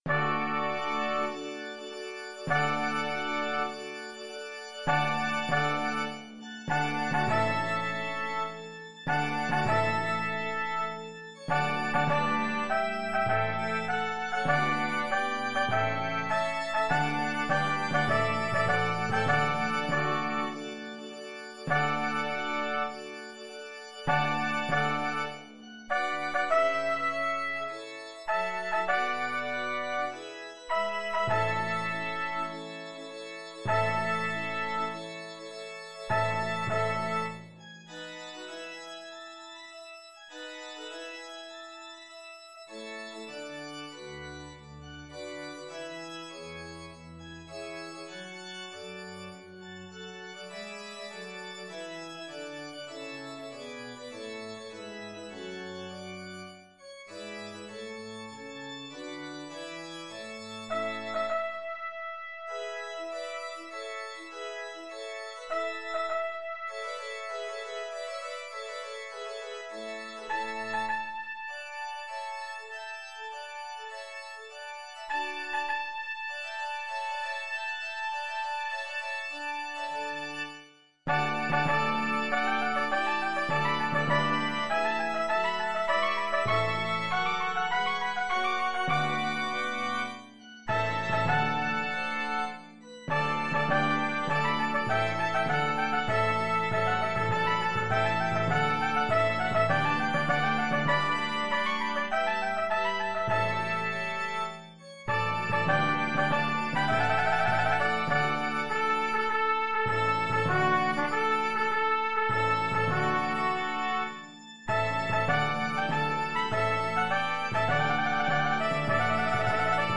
Multiple Soloists and Ensemble  (View more Intermediate Multiple Soloists and Ensemble Music)
Classical (View more Classical Multiple Soloists and Ensemble Music)
concerto-for-3-trumpets-and-timpani-twv-54-d3.mp3